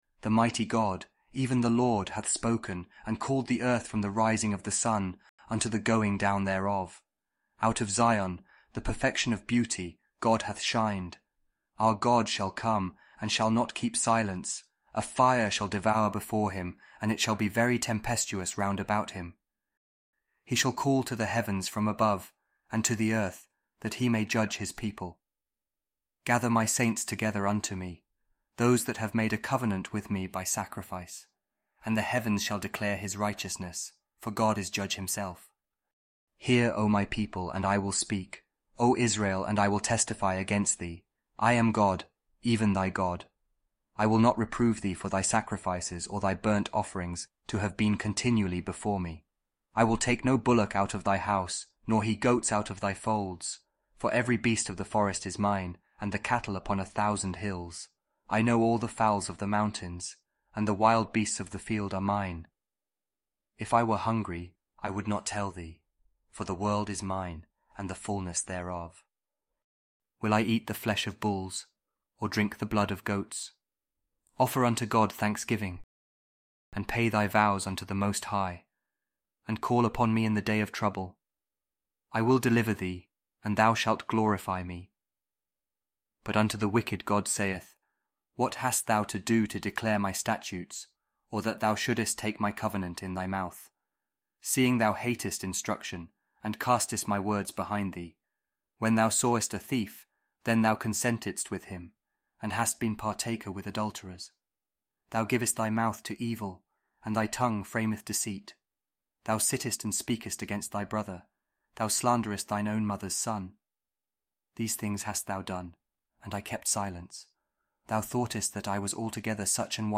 Psalm 50 | King James Audio Bible